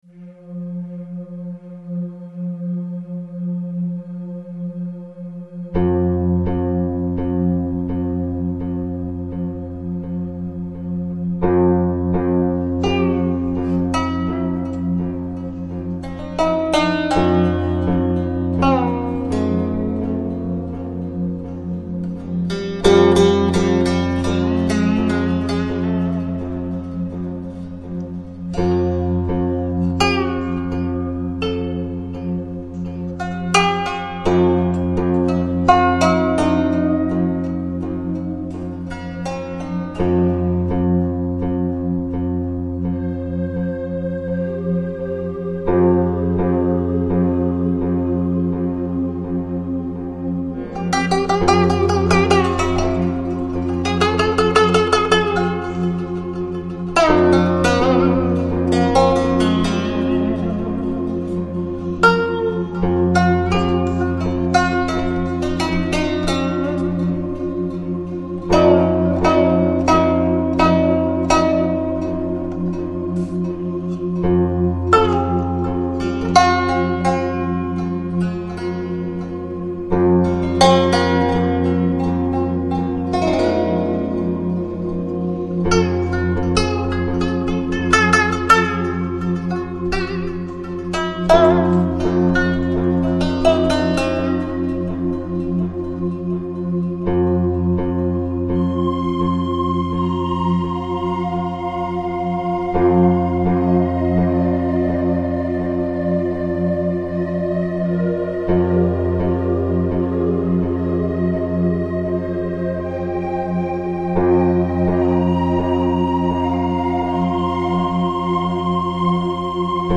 Electronic, Lounge, Chill Out, Downtempo, Balearic Носитель